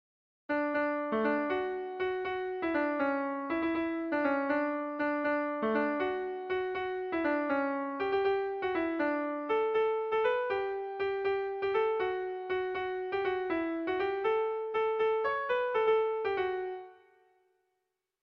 Bertso melodies - View details   To know more about this section
Tragikoa
A1A2B